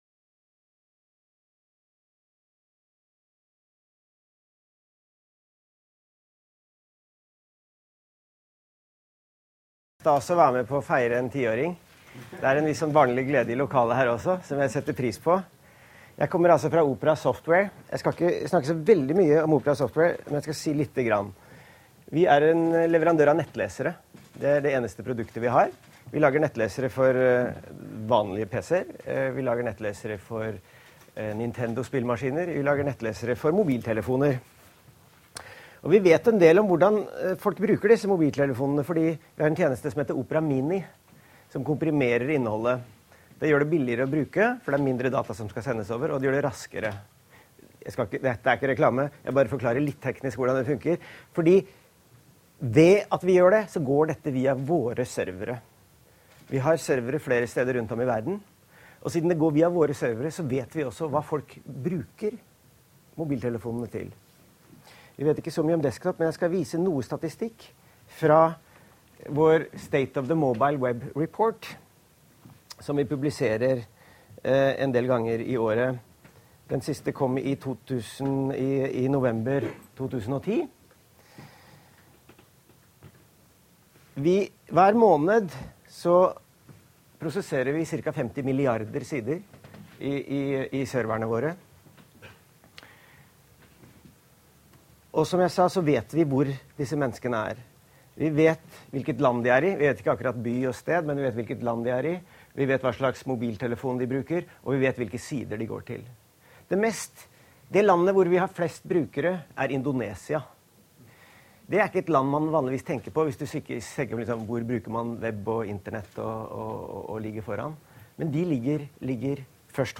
Under W10 i Oslo var vi så heldige å få besøk av web-pioneren Håkon Wium Lie fra Opera Software.